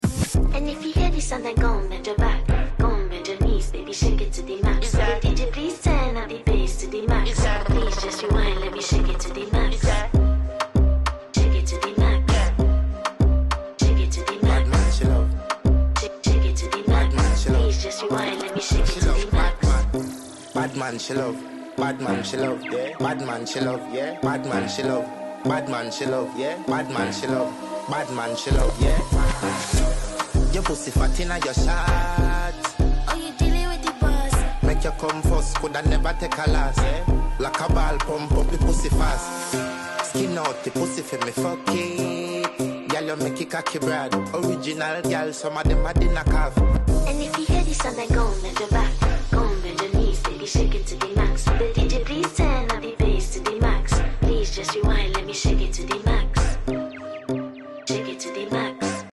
🔧✨ Supercars, classics, muscle beasts & custom builds all in one place! 🏎💨 Turn up the volume & enjoy the engines roar!